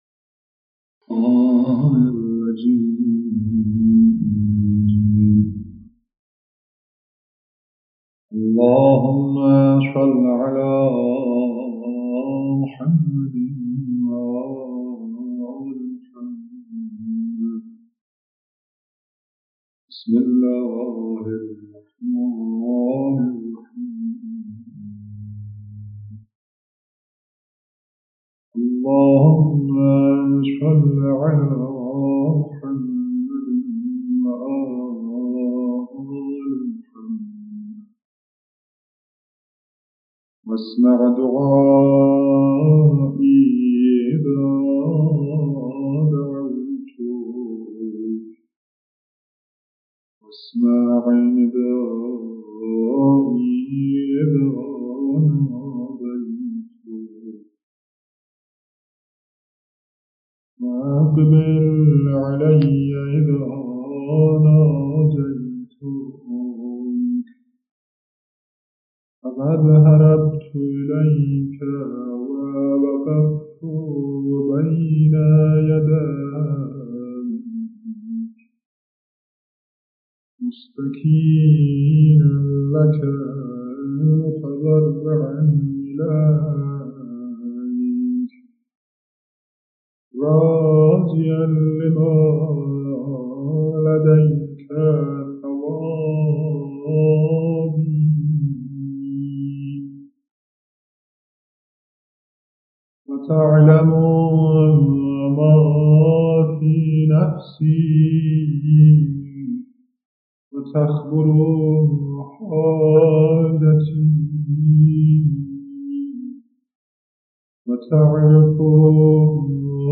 Qeraat_Monajate_Shabaneia.mp3